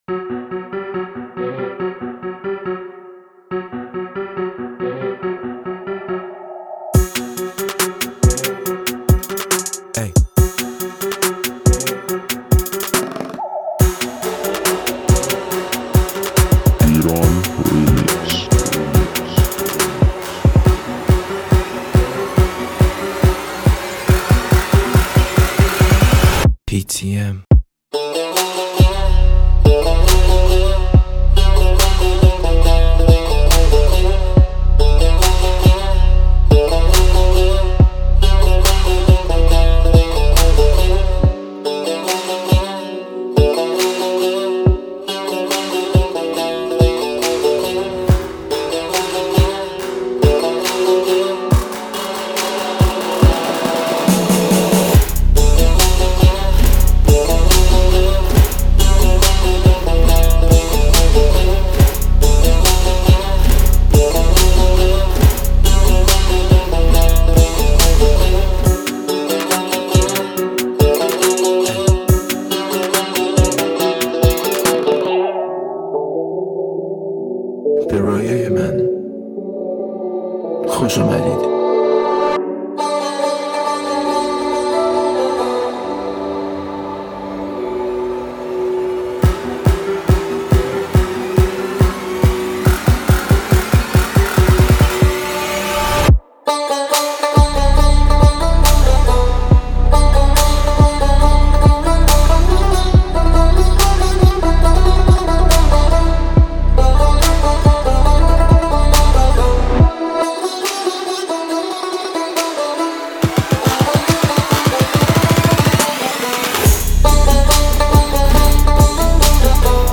Download Iranian trap remix